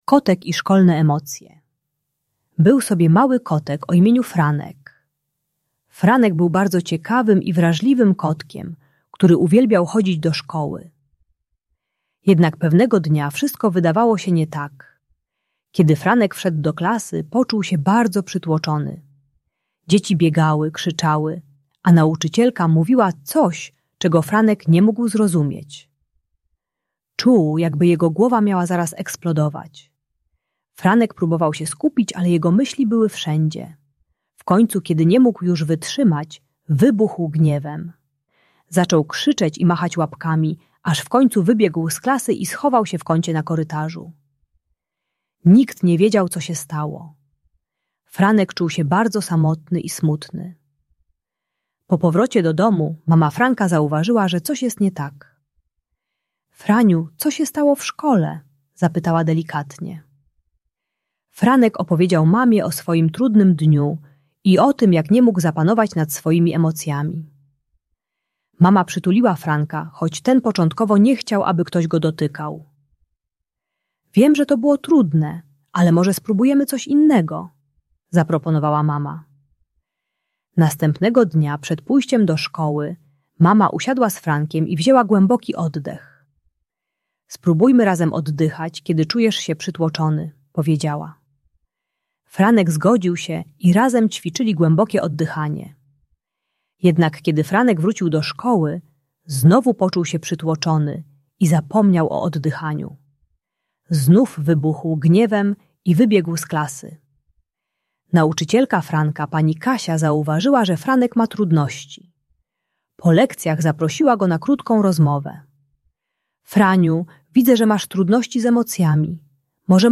Kotek i Szkolne Emocje - Bunt i wybuchy złości | Audiobajka